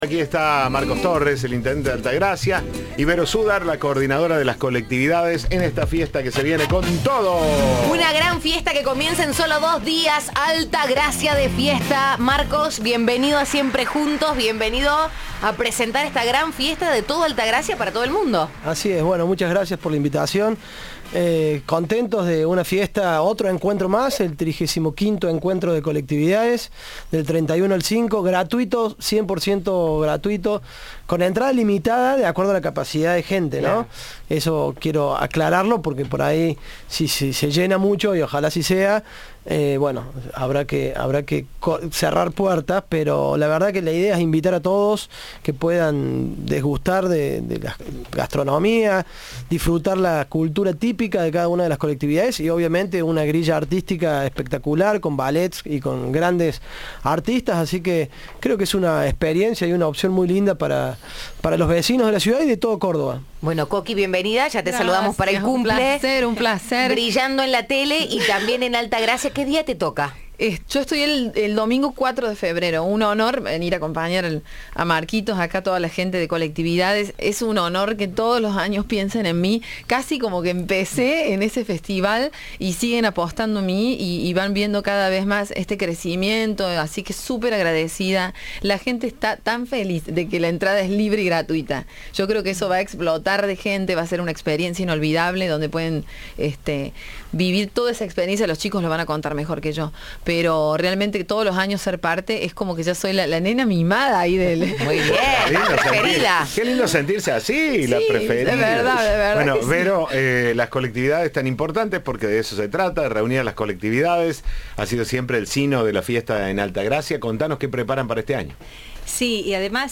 Cadena 3 habló con el intendente Marcos Torres